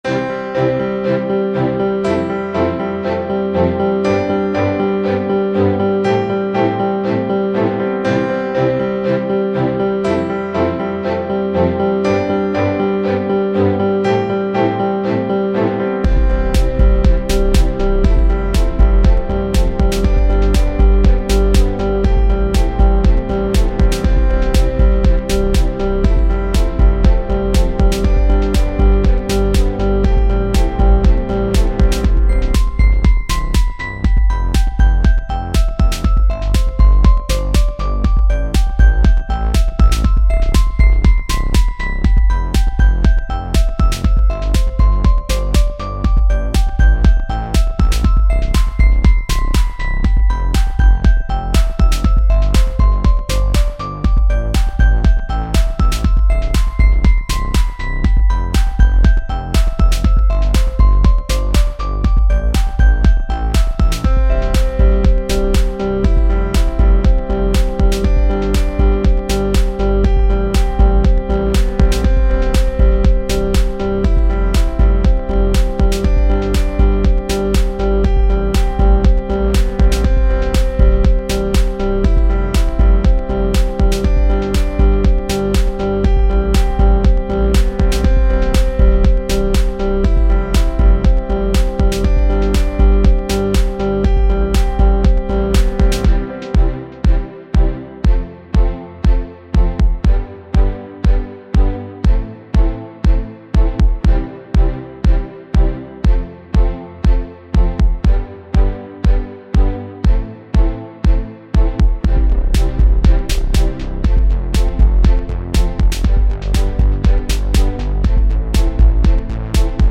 royalty free music
02:59 Electronica 4.2 MB